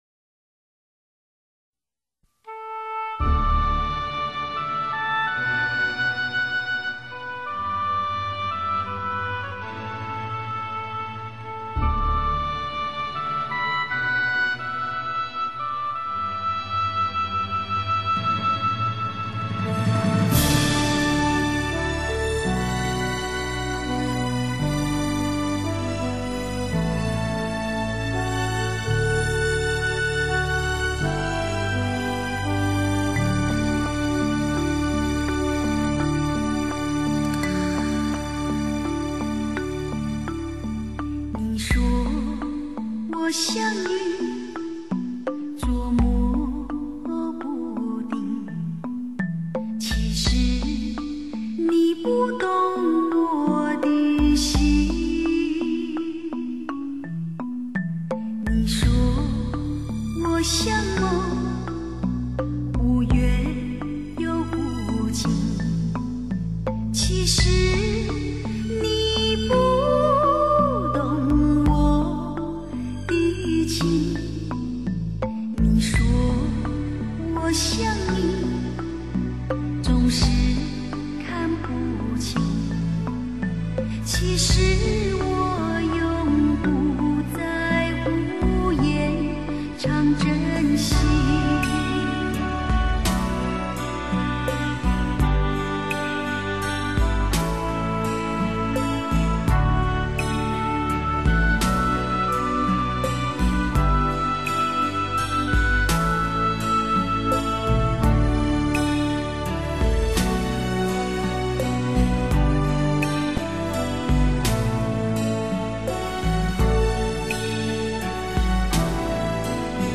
这首熟悉的歌曲，被歌唱者演绎的柔情似水，听之动容